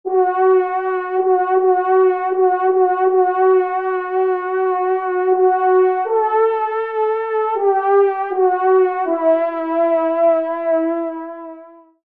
Genre : Fantaisie Liturgique pour quatre trompes
Pupitre 1° Trompe                     Pupitre 2°Trompe